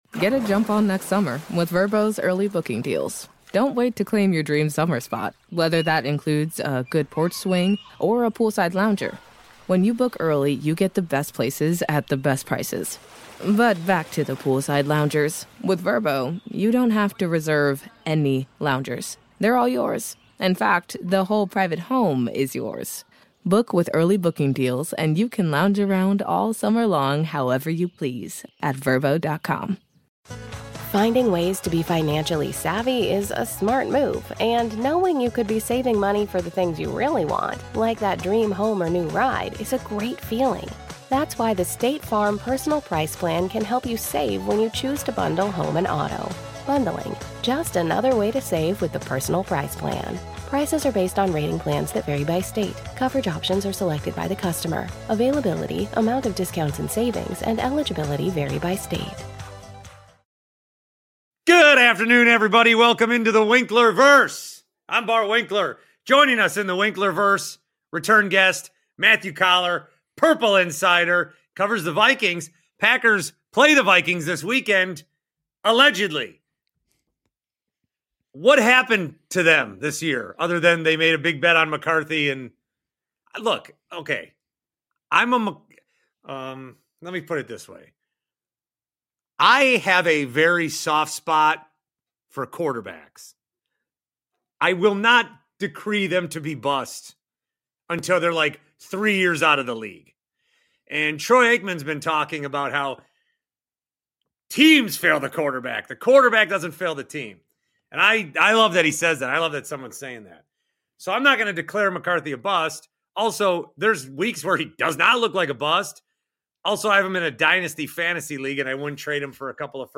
Instead, the guys talk QB development when it comes to both JJ McCarthy and Jordan Love. Plus some voicemails!